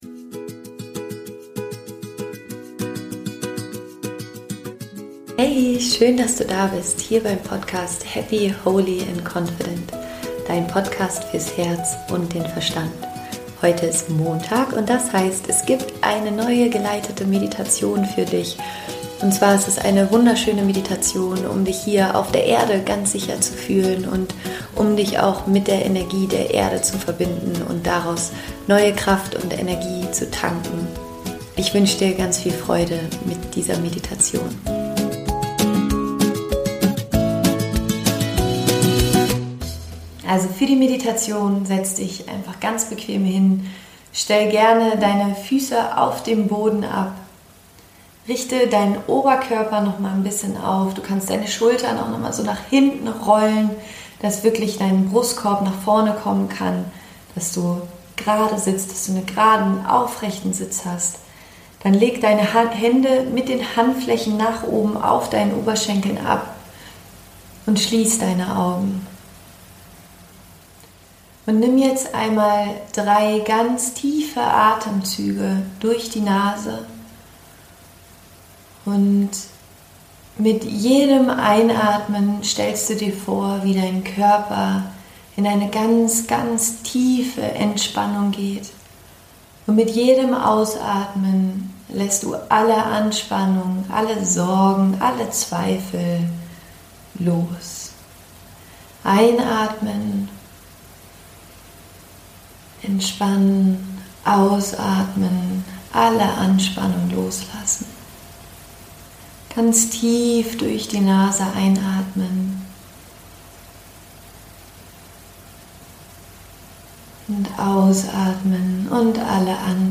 Meditation: Finde inneren Halt und Stärke